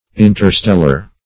Interstellar \In`ter*stel"lar\, a.